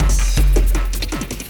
53 LOOP 03-L.wav